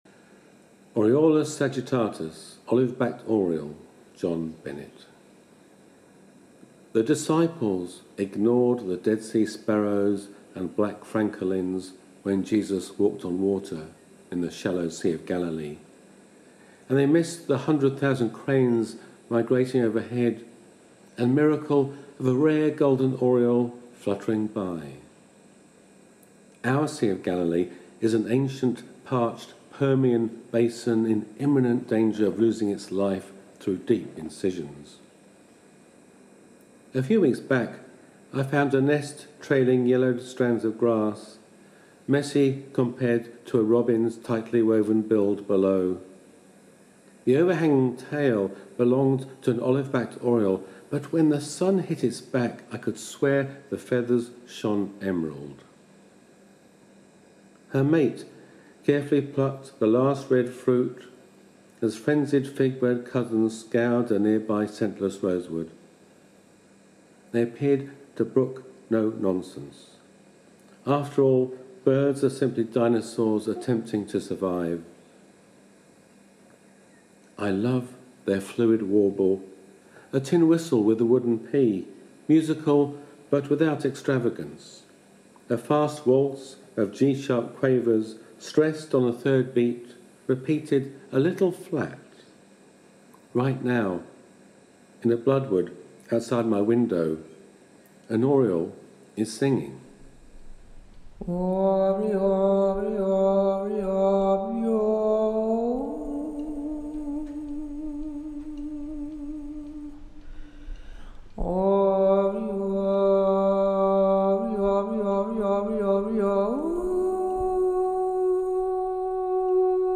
voice.